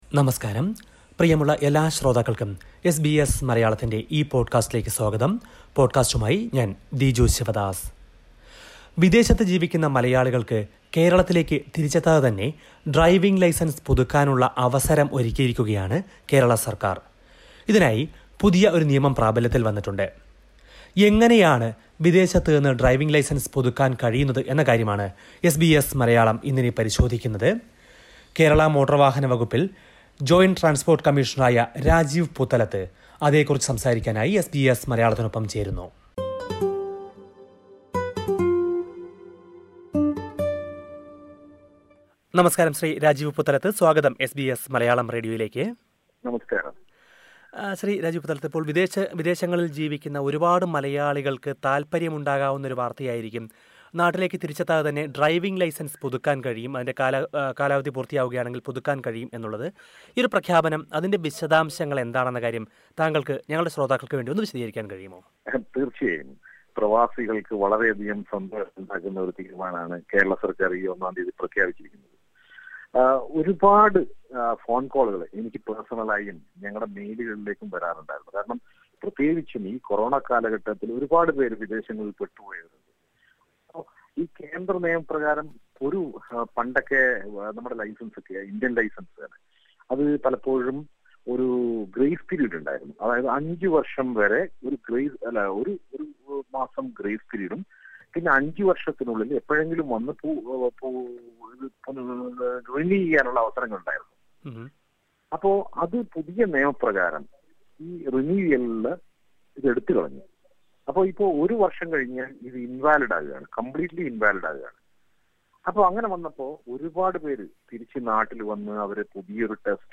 The Kerala government has introduced a new scheme to allow Non Resident Keralites to renew their Indian driving license online, removing the requirement to visit an RTO directly. Rajiv Puthalath, Joint Transport Commissioner in Kerala, explains the details to SBS Malayalam.